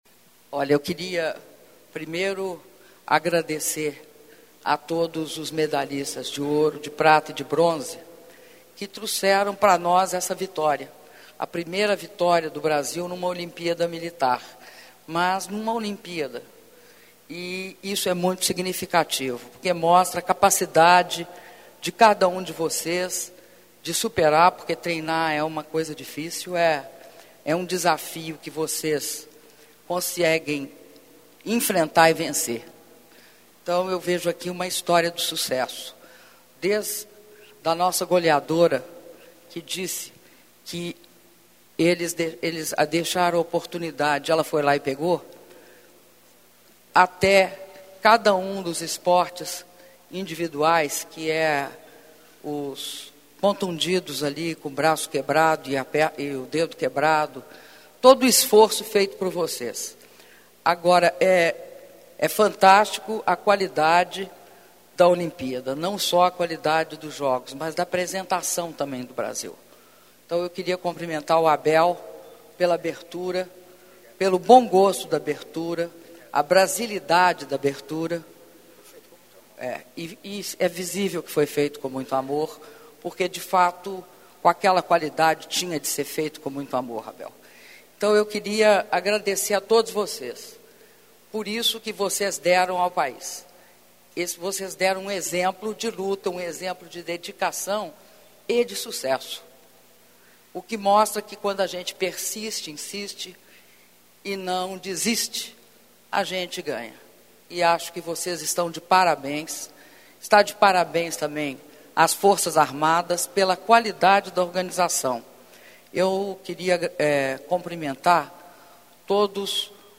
Palavras da Presidenta da República, Dilma Rousseff, durante encontro com a delegação brasileira dos medalhistas dos 5º Jogos Mundiais Militares Rio 2011 - Brasília/DF
Palácio do Planalto, 29 de julho de 2011